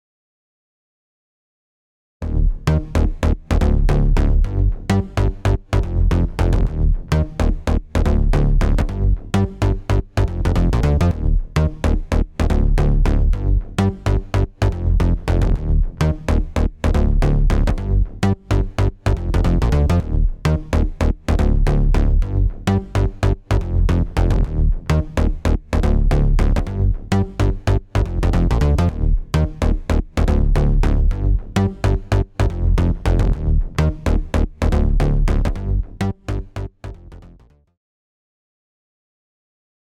ドラムやベースに使うと、アタックが強調され、低音もスッキリして、音が前に出てくる感覚が得られます
OFF（ベース単体）